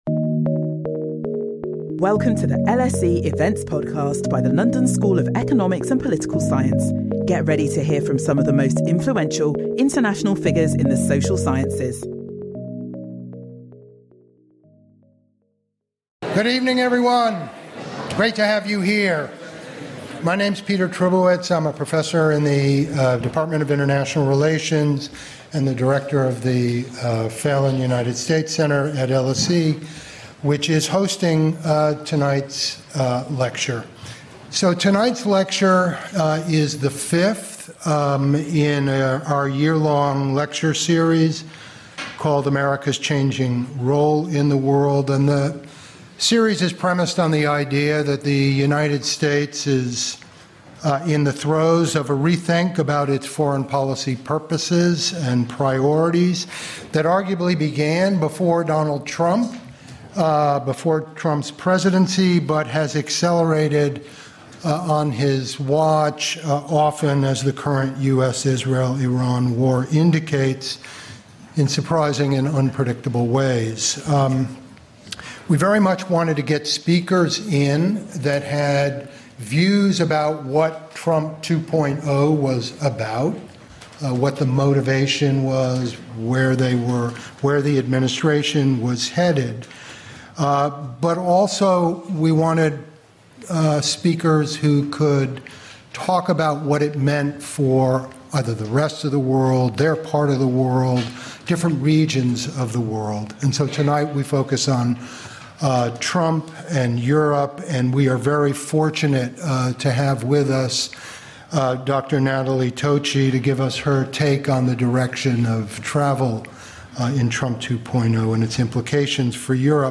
LSE: Public lectures and events